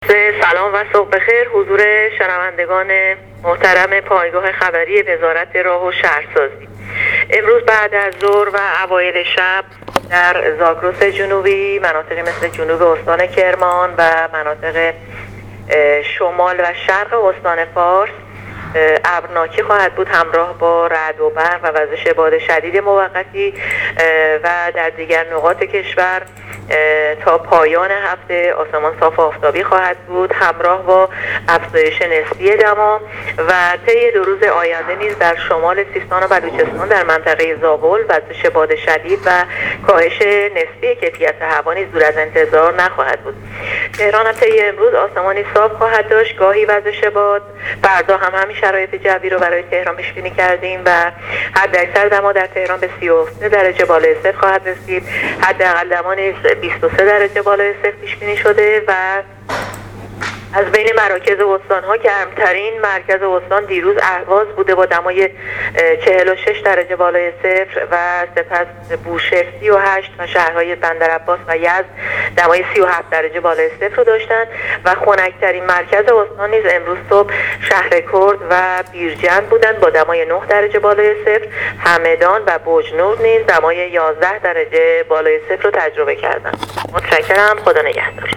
کارشناس سازمان هواشناسی در گفتگو با راديو اينترنتی پايگاه خبری آخرين وضعيت هوا را تشریح کرد.